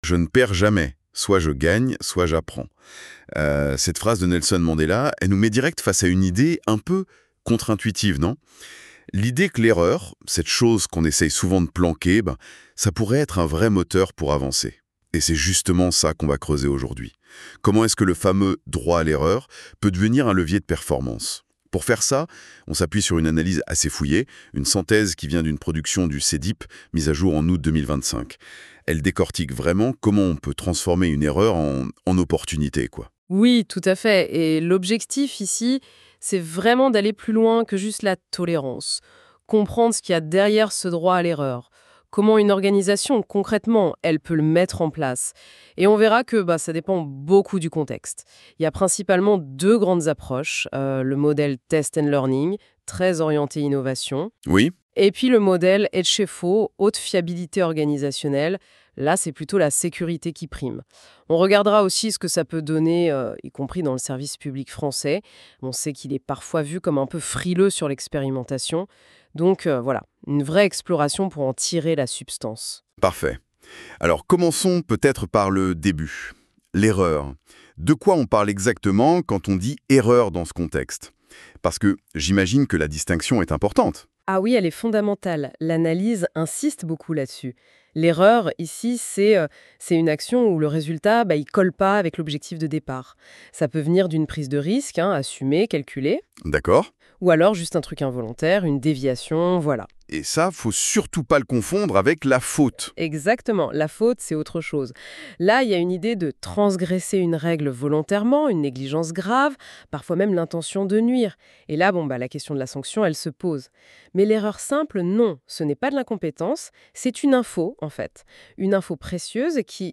Entretien : Le droit à l’erreur
Podcast généré à l'aide de l'IA NotebookLM